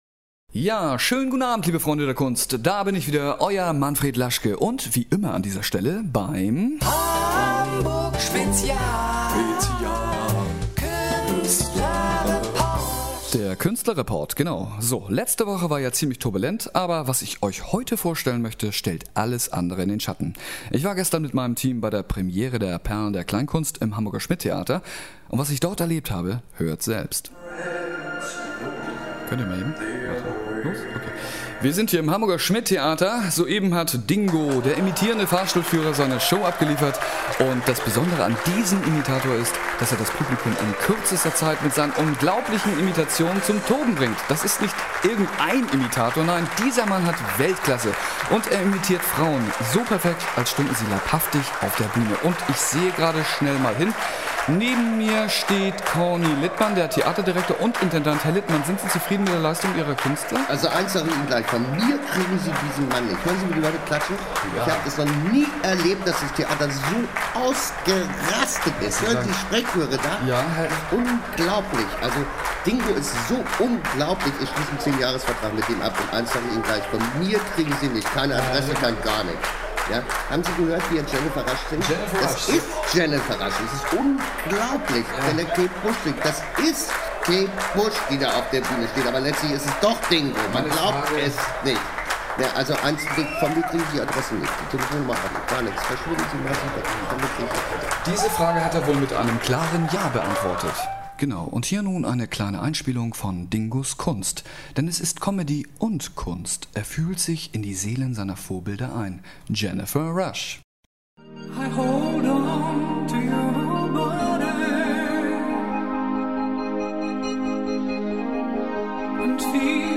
Radioreportage
mit Original Szenen aus dem Schmidt-Tivoli.